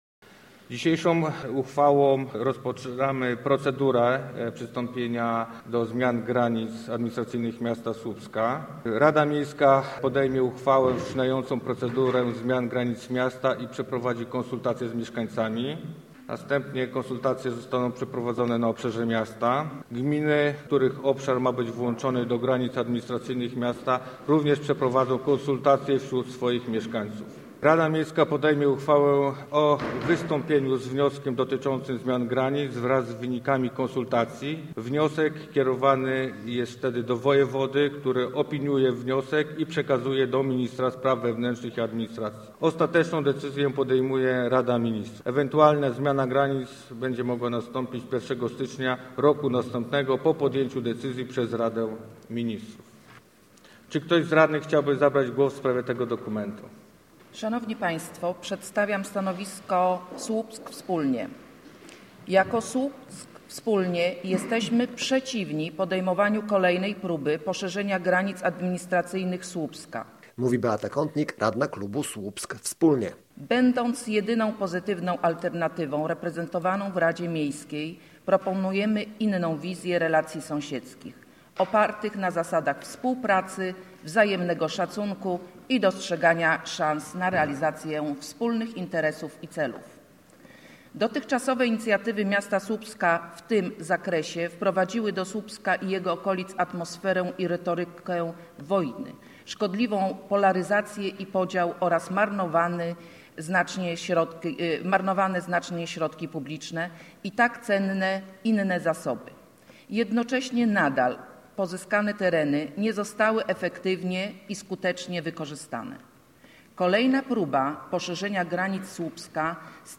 – Nasz klub jest przeciw – podkreślała na sesji Beata Kątnik, radna Słupsk Wspólnie – Proponujemy inną wizję relacji sąsiedzkich.